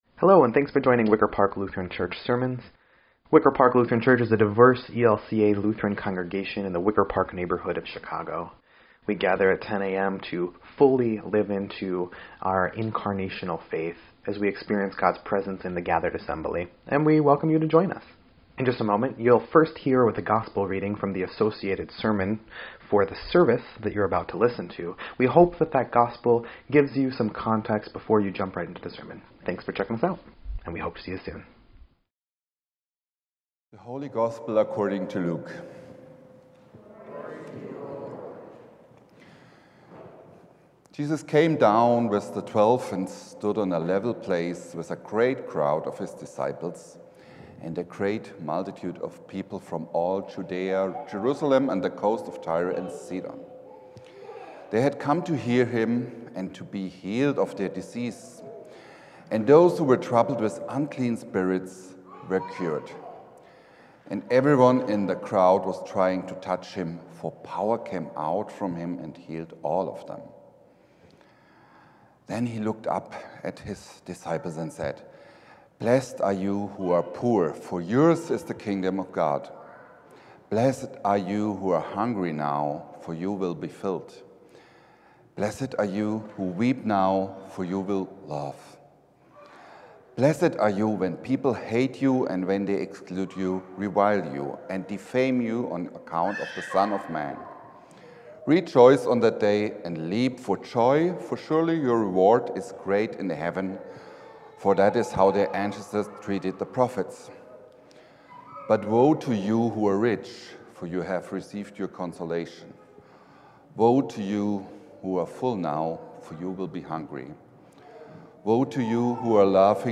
2.16.25-Sermon_EDIT.mp3